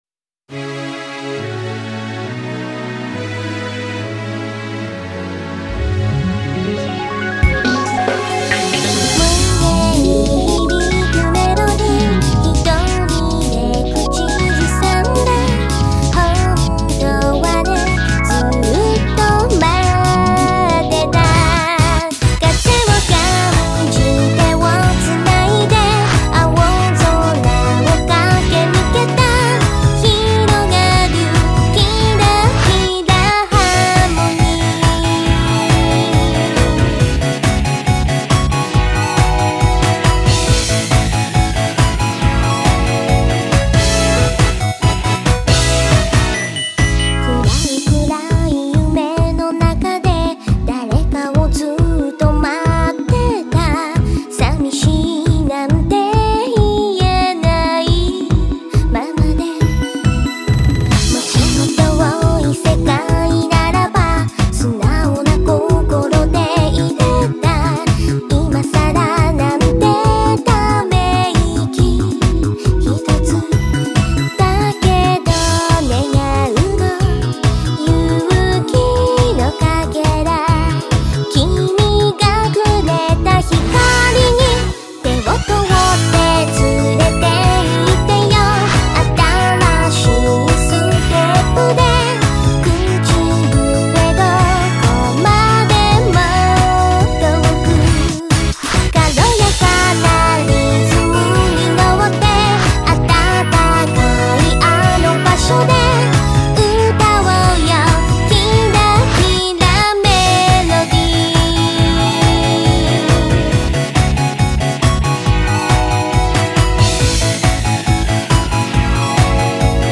■ エンディング曲
（MP3形式 / 1コーラス fadeout ver. / 2MB）
作詞・ボーカル